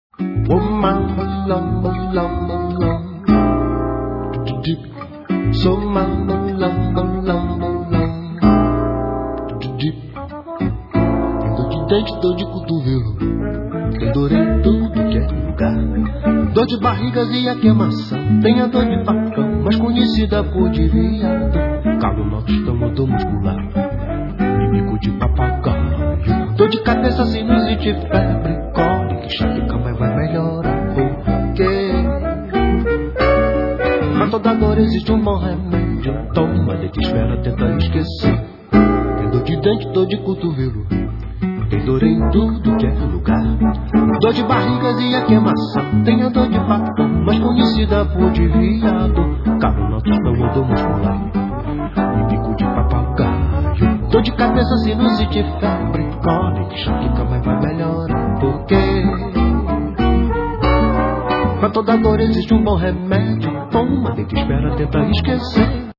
Guitarist, Composer, Lyricist